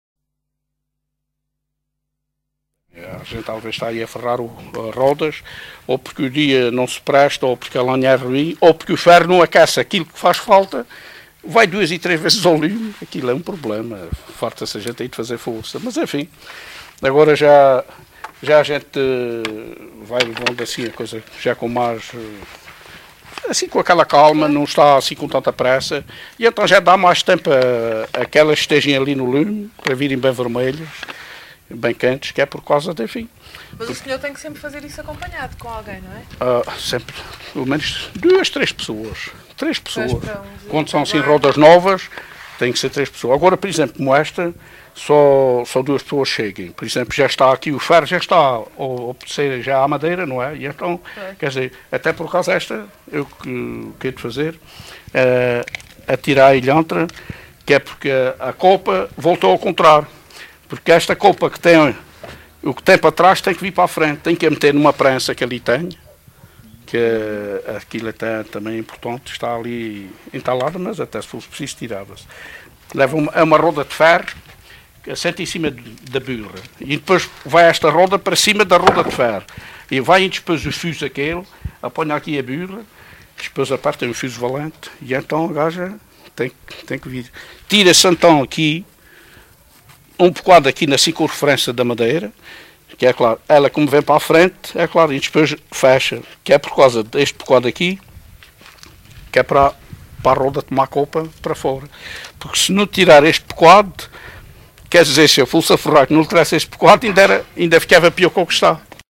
LocalidadeCastelo de Vide (Castelo de Vide, Portalegre)